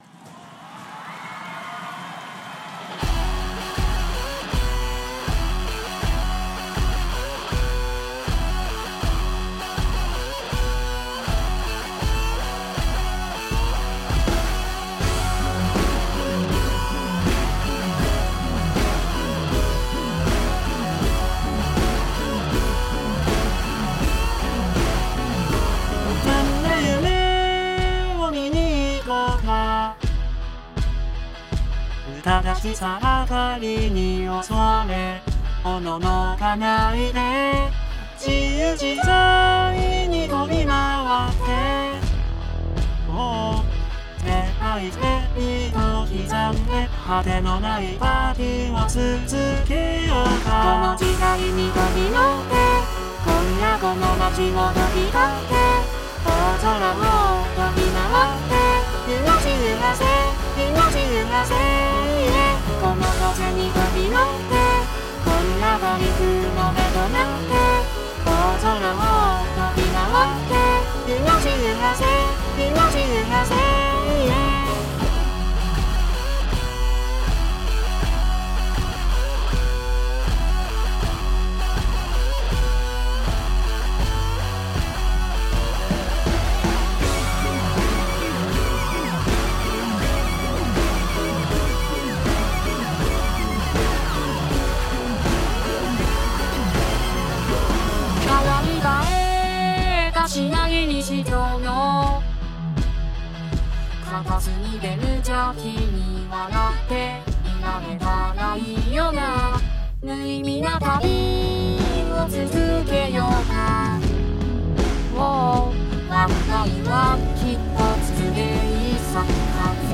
立体声